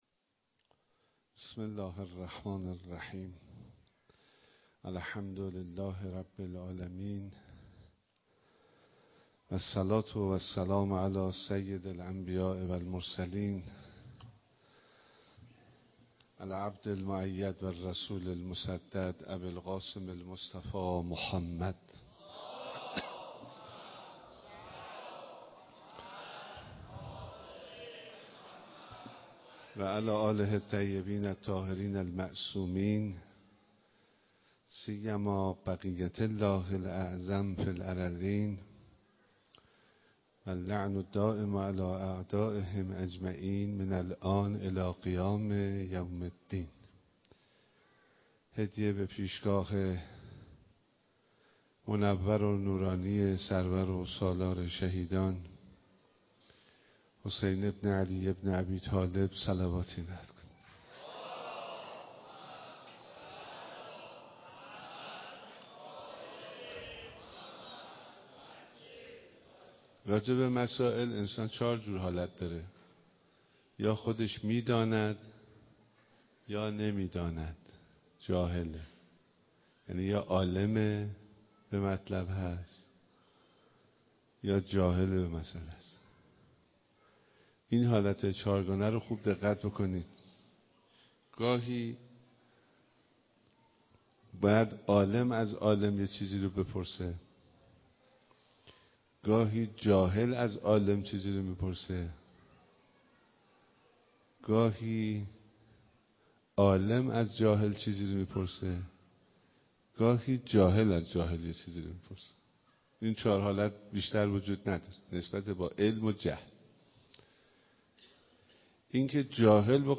صوت/سخنرانی آیت‌الله حسینی همدانی در چهارمین شب مراسم عزاداری حضرت اباعبدالله الحسین علیه‌السلام
به گزارش روابط عمومی دفتر نماینده مقام معظم رهبری در استان البرز و امام‌جمعه کرج، چهارمین شب مراسم عزاداری سید و سالار شهیدان حضرت اباعبدالله الحسین علیه‌السلام و یاران باوفای ایشان، با حضور و سخنرانی نماینده رهبر معظم انقلاب اسلامی در استان البرز در مصلی امام خمینی رحمهالله‌علیه برگزار شد.